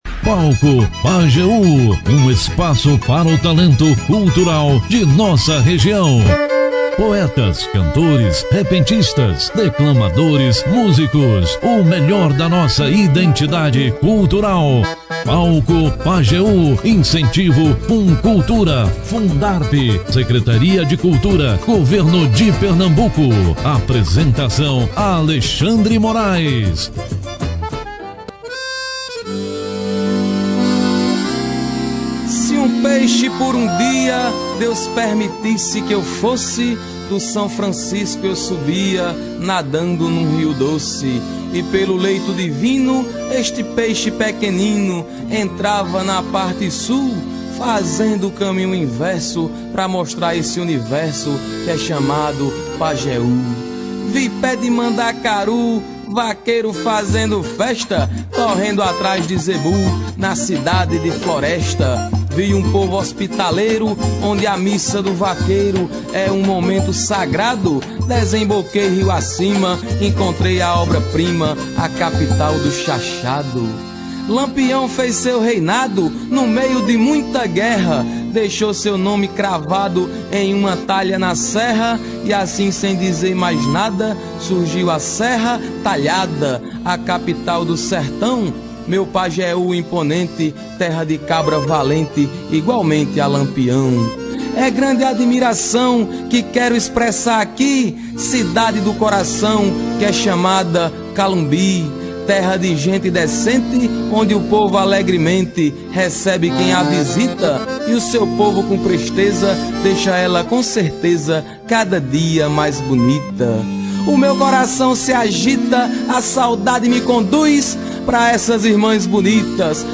O programa foi recheado com boas histórias e muita música de qualidade.